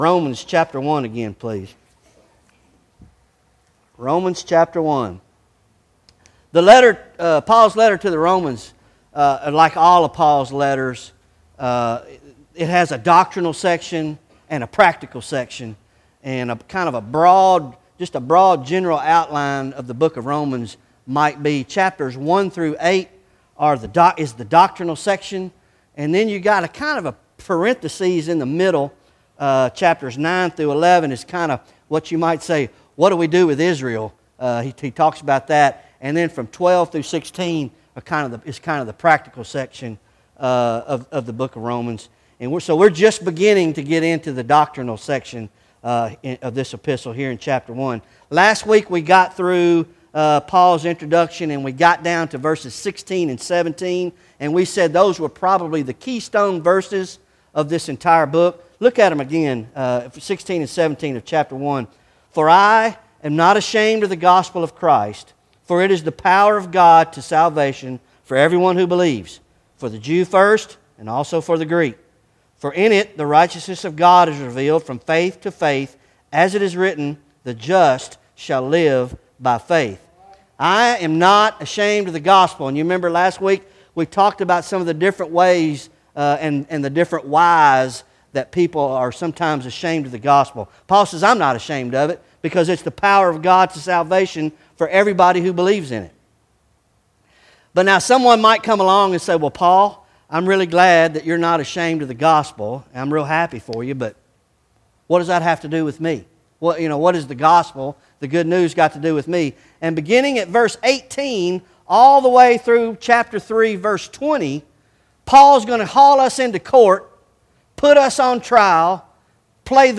Bible Study Romans Ch 1 18-32